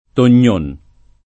Tognon
[ ton’n’ 1 n ]